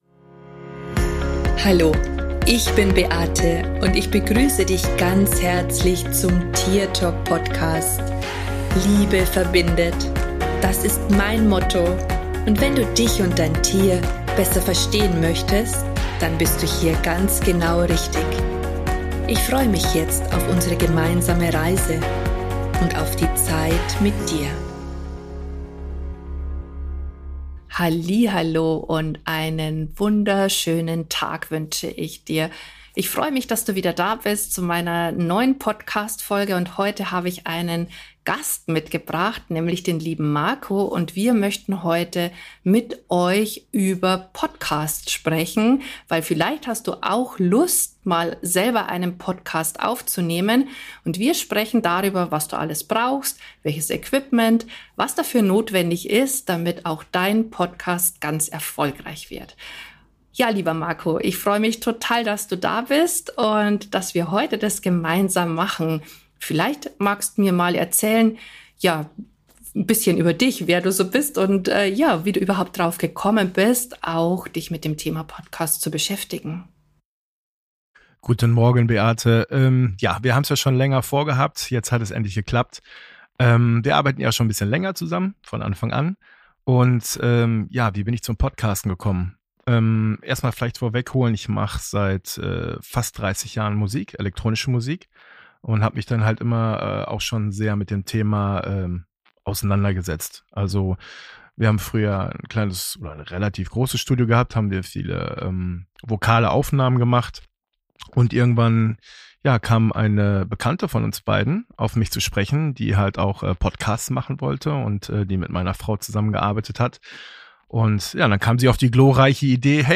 Das Interview soll dir dabei helfen endlich zu starten.